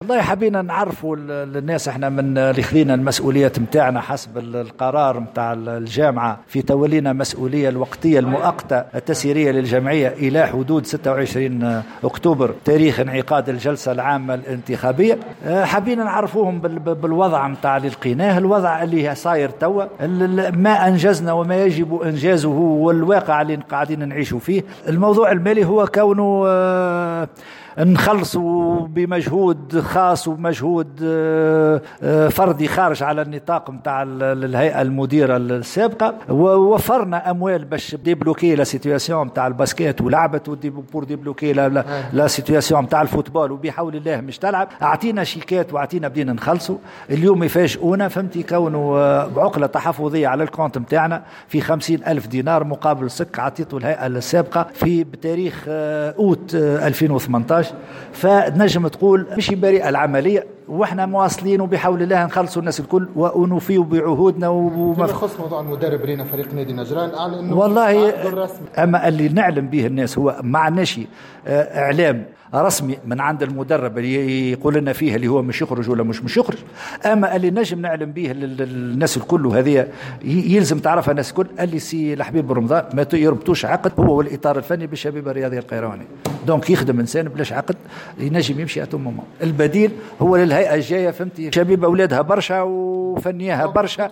عقدت الهيئة التسييرية للشبيبة القيروانية ندوة صحفية اليوم الإربعاء 03 أكتوبر 2018 بمقر النادي للحديث حول الوضعية الحالية للنادي.